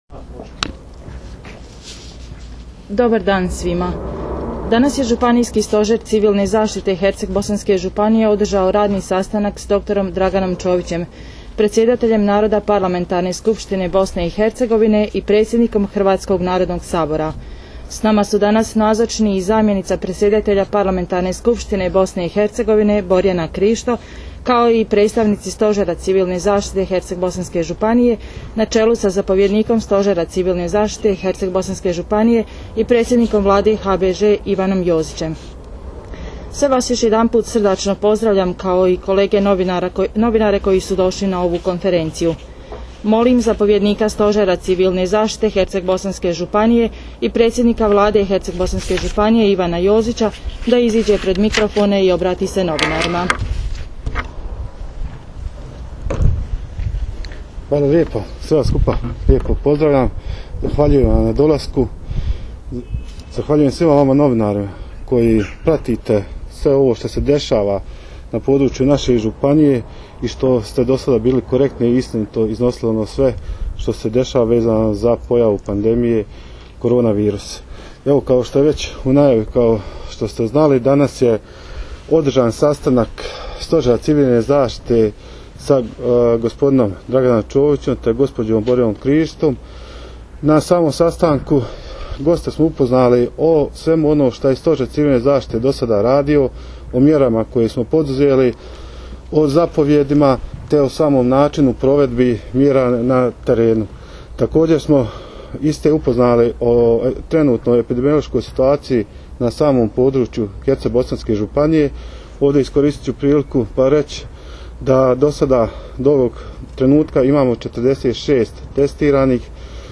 Konferenciju za novinare održali su zapovjednik Stožera civilne zaštite HBŽ i predsjednik Vlade Ivan Jozić i dr. Dragan Čović, predsjedatelj Doma naroda Parlamentarne skupštine Bosne i Hercegovine i predsjednik Hrvatskog narodnog sabora možete je preuzeti OVDJE